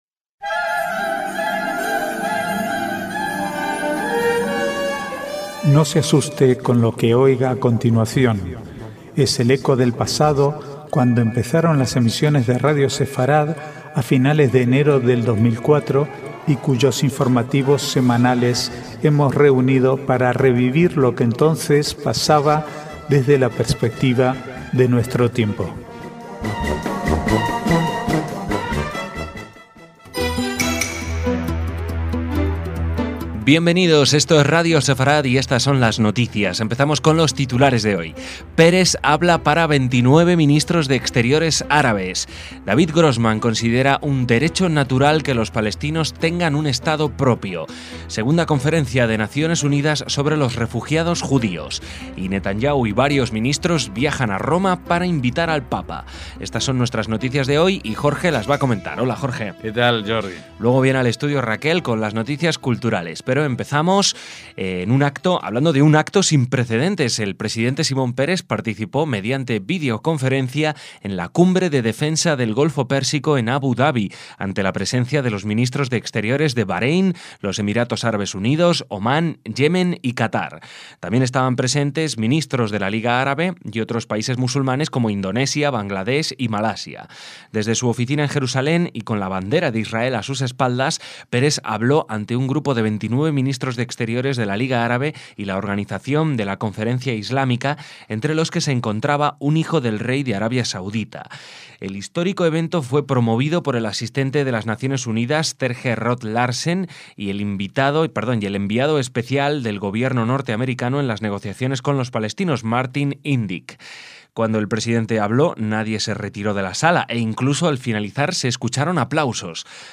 Es el eco del pasado, cuando empezaron las emisiones de Radio Sefarad a finales de enero de 2004 y cuyos informativos semanales hemos reunido para revivir lo que entonces pasaba desde la perspectiva de nuestro tiempo.